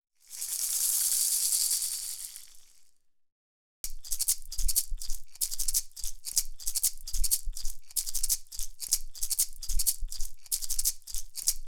These vibrant coloured feather gourd or coconut rattles are called ‘Ulī’ulī (another unique invention to the Hawaiian islands) used to help hula dancers tell their stories in exciting and creative ways.
‘Ulī’ulī